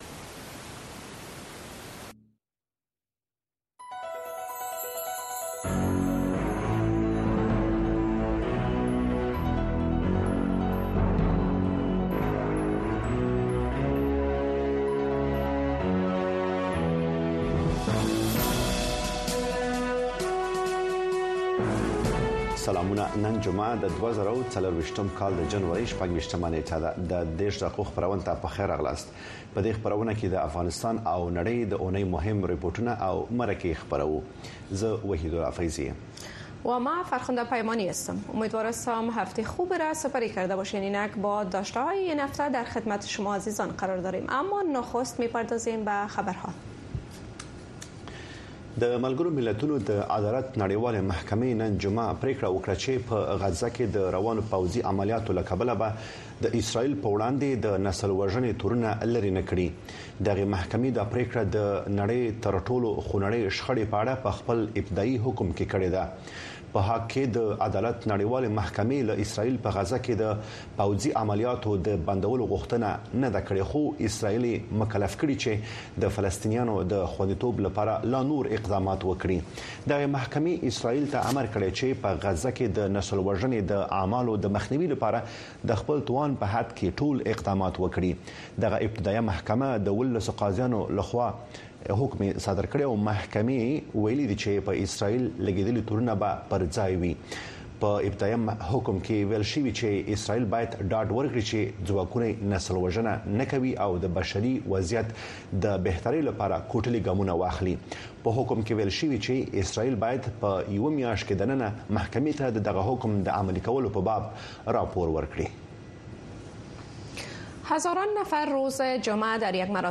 د ۳۰ دقیقې په اونیزه خپرونه کې د اونۍ مهمو پیښو، رپوټونو او مرکو ته بیاکتنه کیږي او د افغانستان د ورځنیو پیښو په اړه تازه او هر اړخیرې ارزونې وړاندې کیږي.